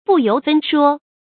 注音：ㄅㄨˋ ㄧㄡˊ ㄈㄣ ㄕㄨㄛ
不由分說的讀法